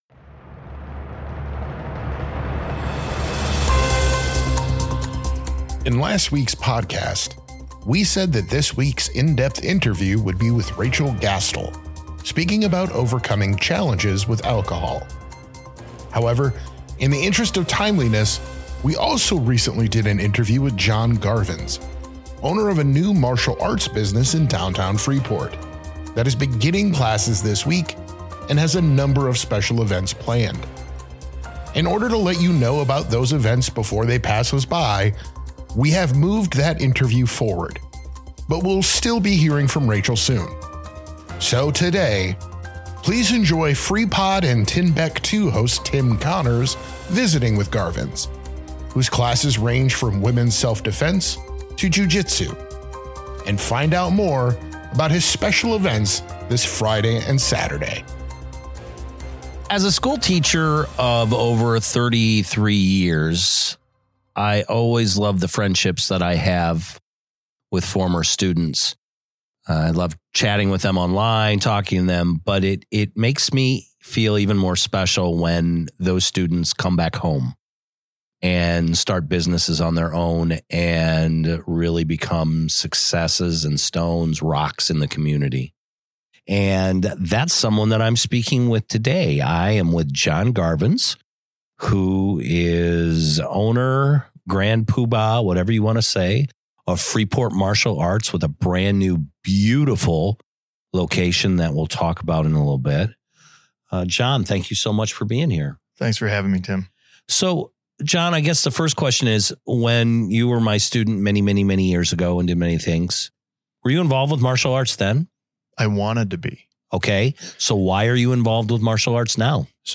Freepod - Freepod Interview: Freeport Martial Arts